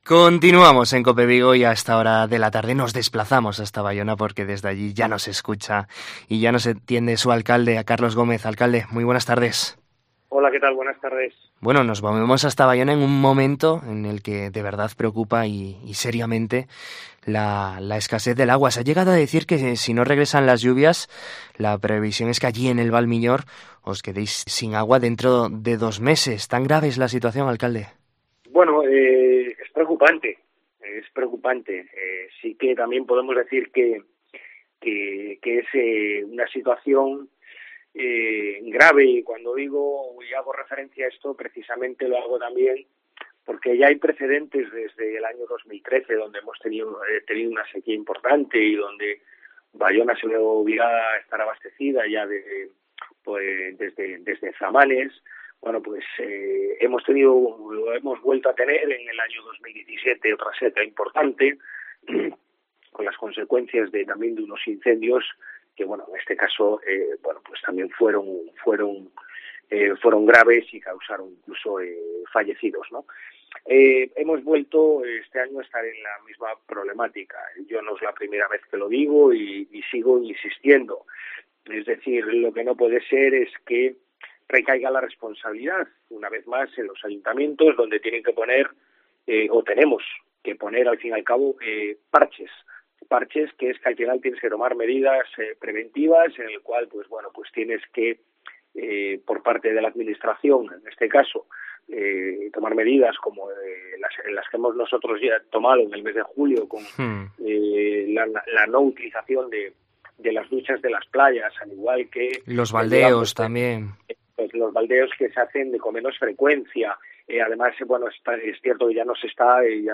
En COPE Vigo conocemos la actualidad de Baiona de la mano de su alcalde, Carlos Gómez